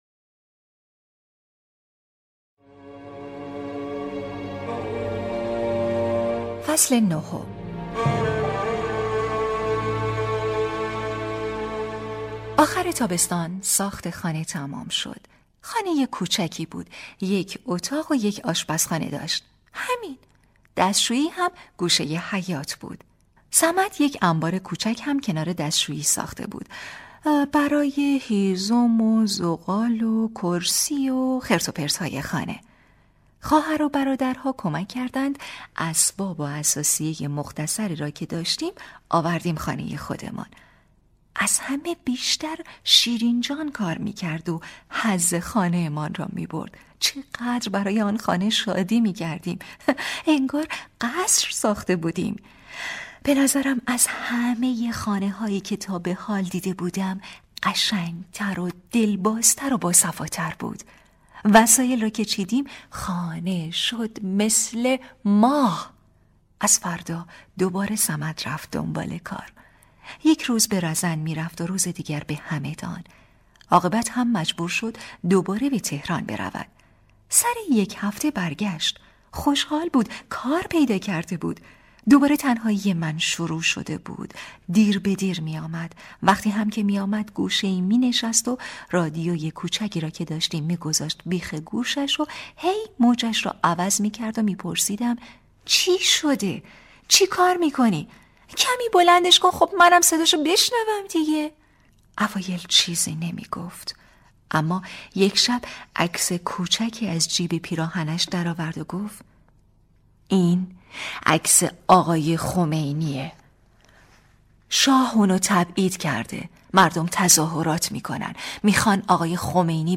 # کتاب صوتی